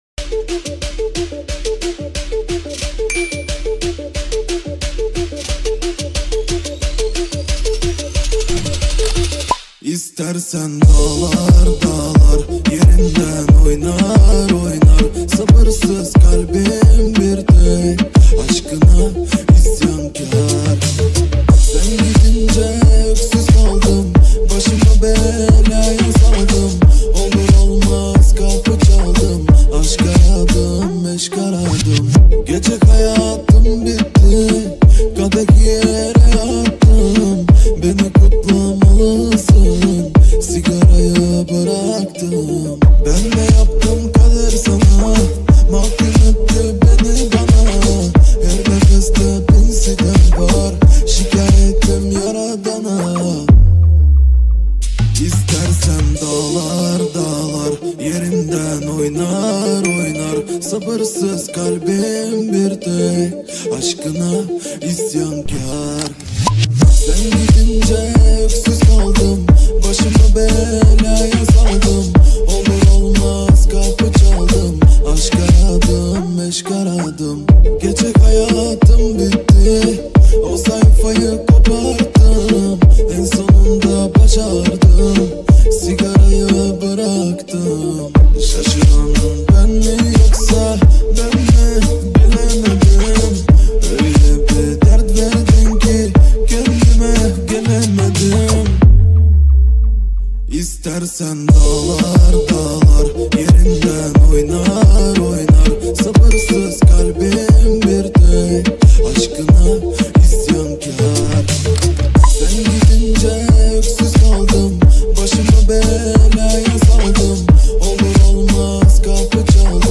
نام اثر: ریمیکس
ژانر: پاپ